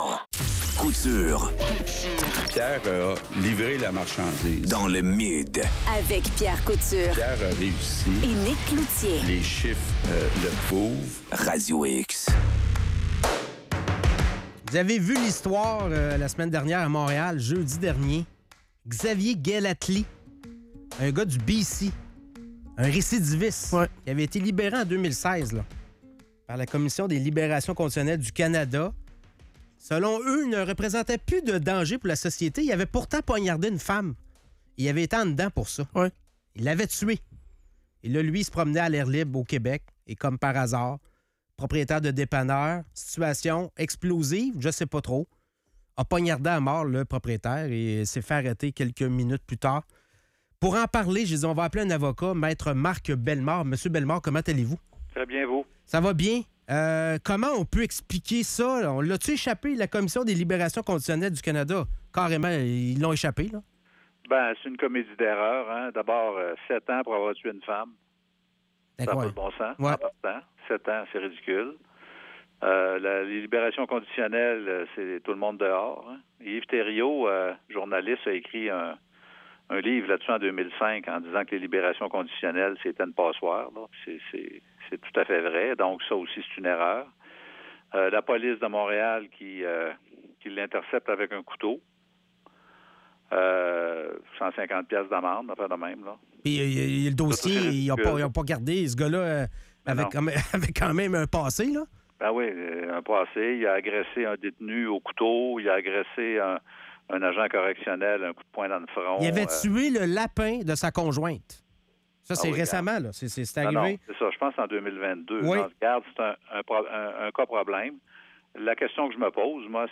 Entrevue avec Marc Bellemarre